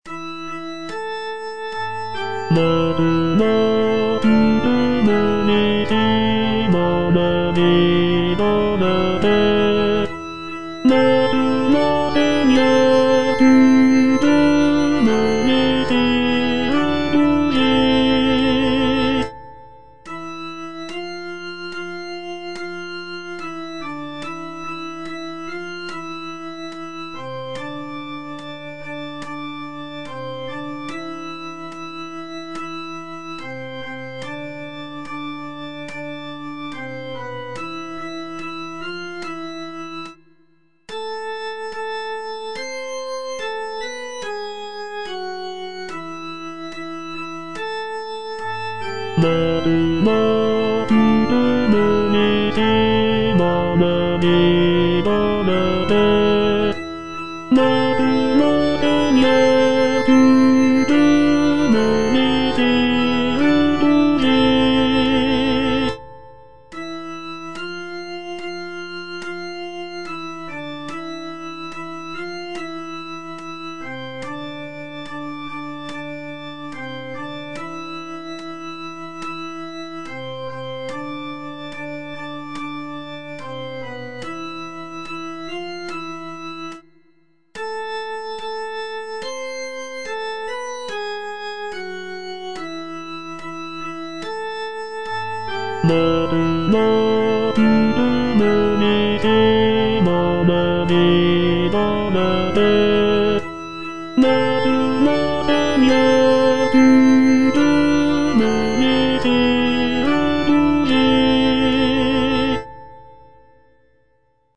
L. MARTENS - MAINTENANT, SEIGNEUR Tenor (Voice with metronome) Ads stop: auto-stop
"Maintenant, Seigneur" is a choral composition by L. Martens.